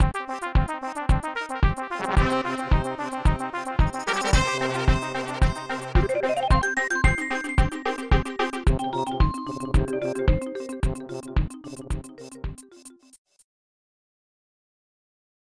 Opening fanfare